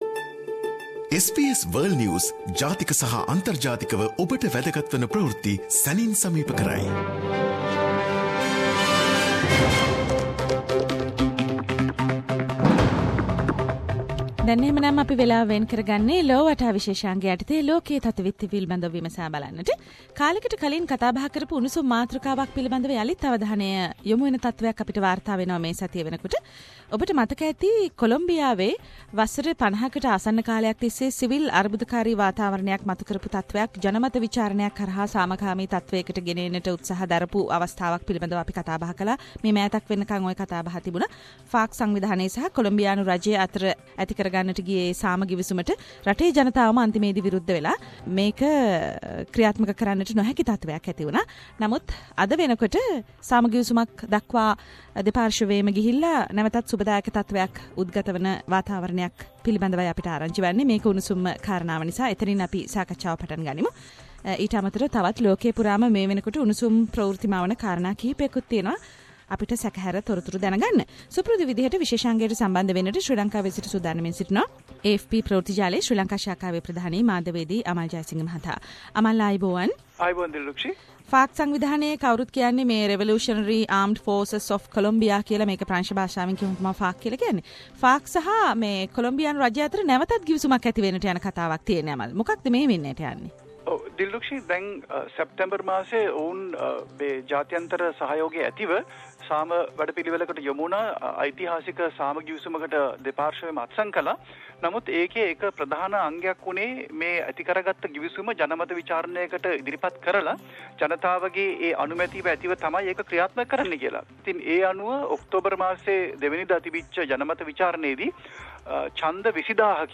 world news wrap